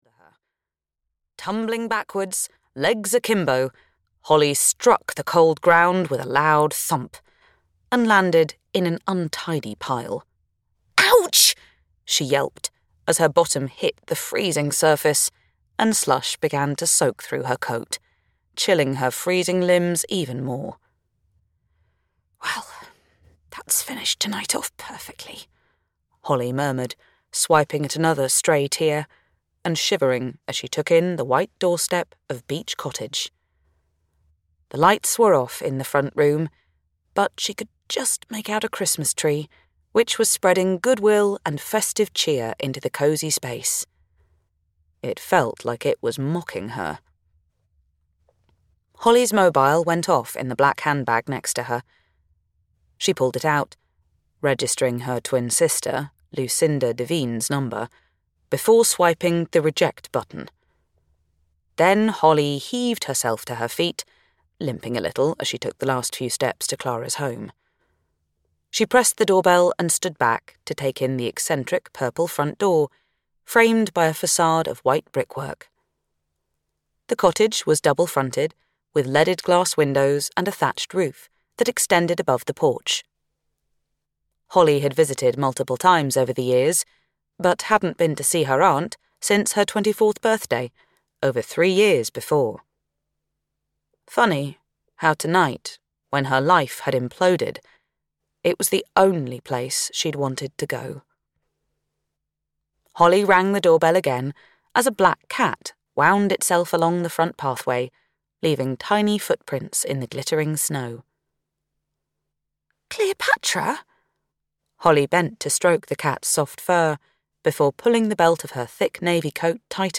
The Christmas Countdown (EN) audiokniha
Ukázka z knihy